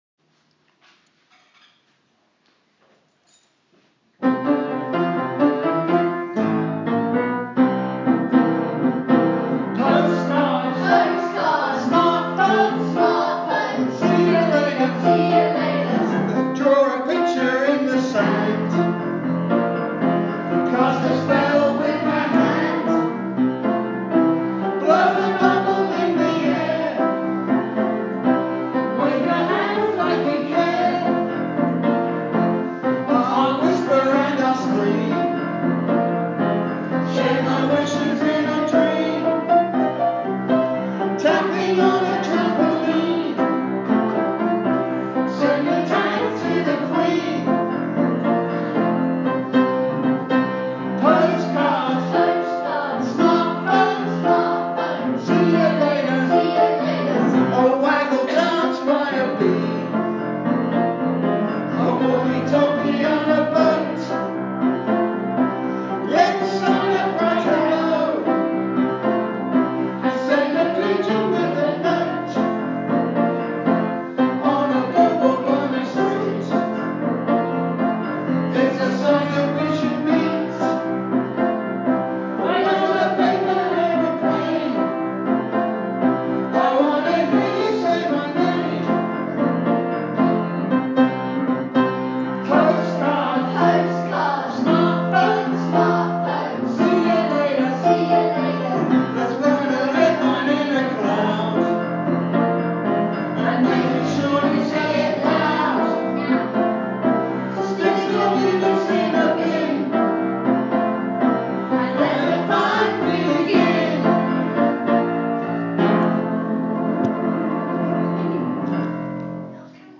Visitors can even listen to a song made by children at Roman Hill and appropriately named ‘Dear Friend I’ve Never Met Before’.